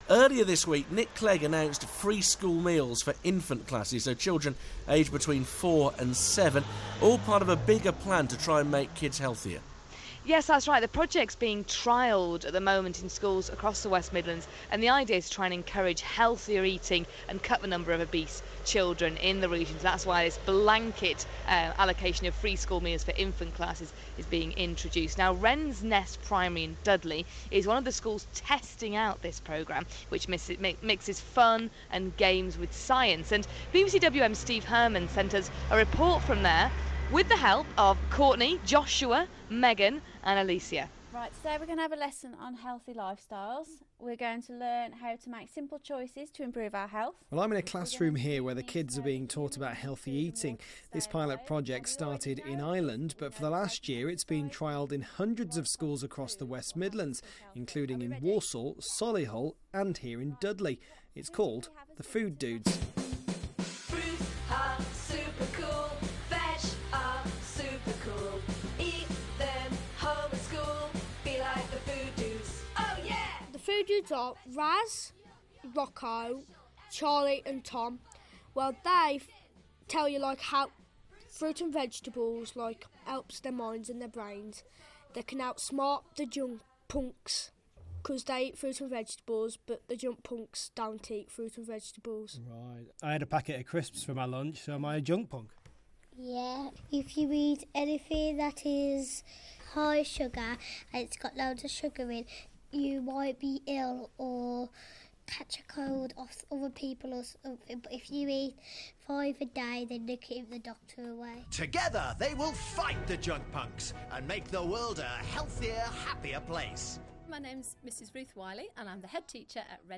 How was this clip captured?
BBC WM Food Dudes Package